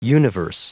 Prononciation du mot universe en anglais (fichier audio)
Prononciation du mot : universe